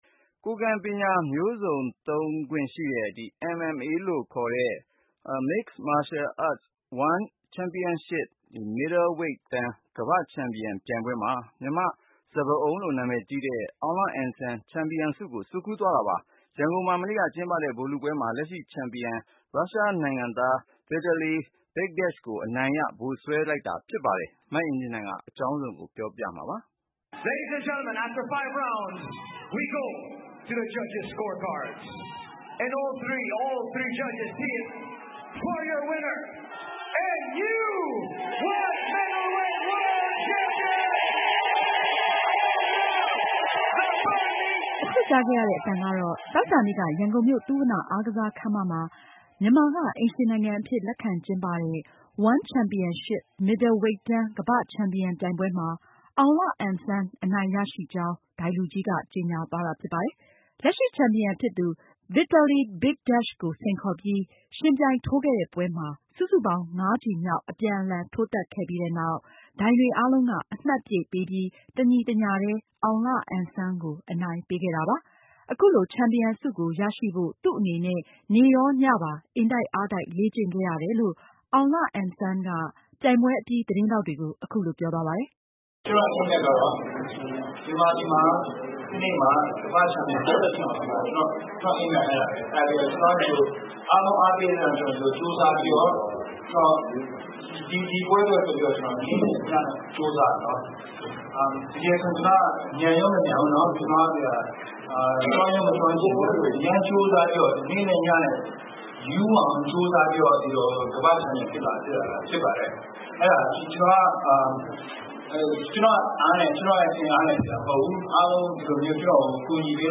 အခုကြားခဲ့ရတဲ့ အသံကတော့ သောကြာနေ့က ရန်ကုန်မြို့ သုဝဏ္ဏအားကစား ခန်းမမှာ မြန်မာက အိမ်ရှင်နိုင်ငံအဖြစ်လက်ခံကျင်းပတဲ့ One Championship မစ်ဒယ်ဝိတ်တန်း ကမ္ဘာ့ချန်ပီယံ ပြိုင်ပွဲမှာအောင်လအန်ဆန် အနိုင်ရရှိကြောင်း ဒိုင်လူကြီးက ကြေညာသွားတာဖြစ်ပါတယ်။